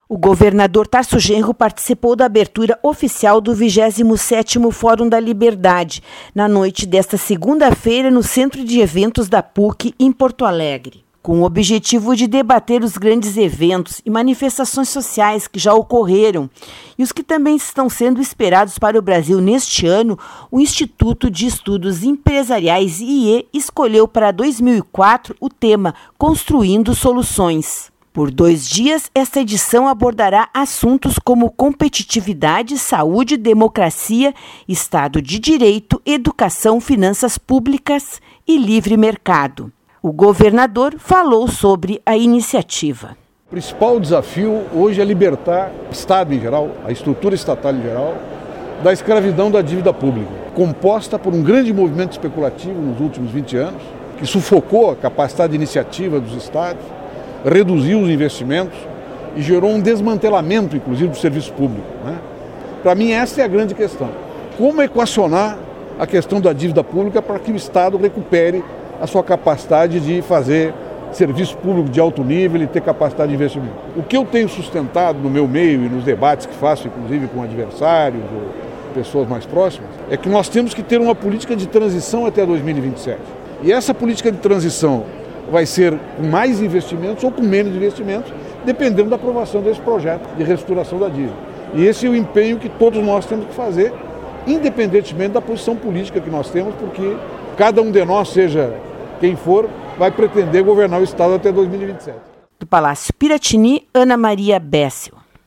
O governador Tarso Genro participou da abertura oficial do 27º Fórum da Liberdade, nesta segunda-feira (7), no Centro de Eventos da Pontifícia Universidade Católica do Rio Grande do Sul (PUCRS), em Porto Alegre.